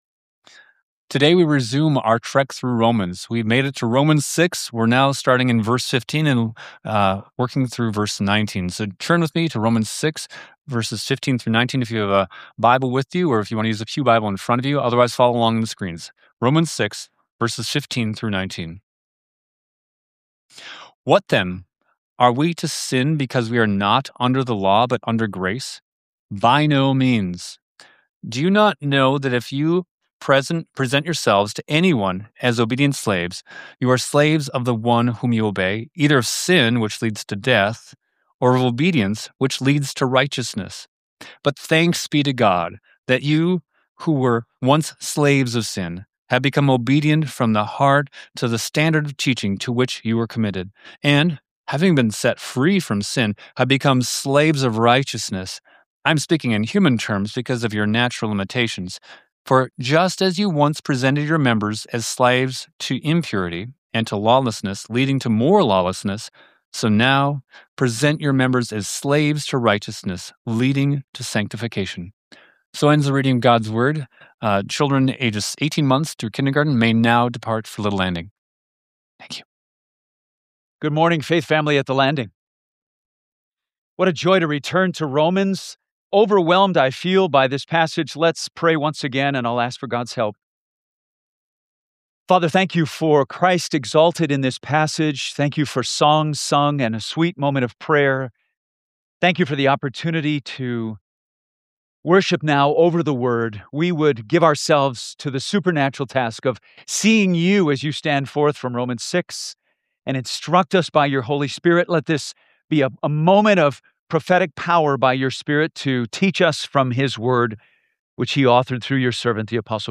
Sermons | The Landing Church